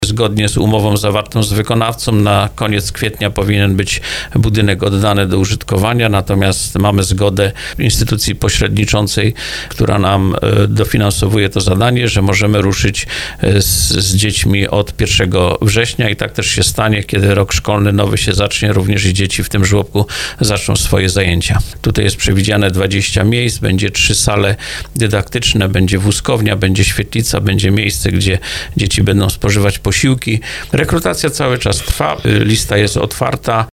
Nowy budynek powstał przy zespole szkół. O postępach w tej inwestycji mówił w audycji Słowo za Słowo wójt gminy Żyraków Marek Rączka.